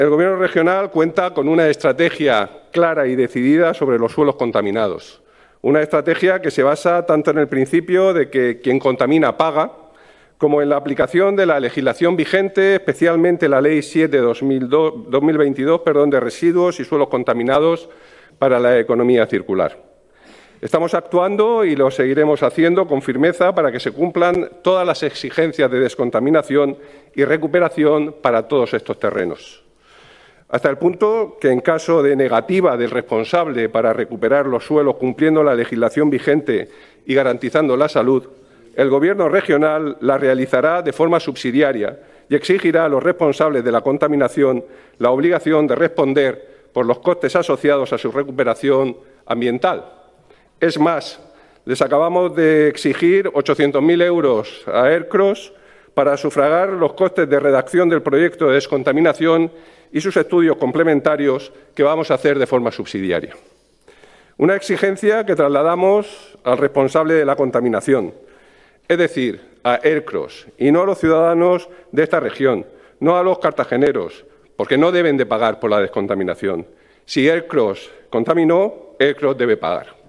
El consejero de Medio Ambiente, Universidades, Investigación y Mar Menor, Juan María Vázquez, durante su comparecencia en la Asamblea Regional, sobre el principio de quien contamina paga.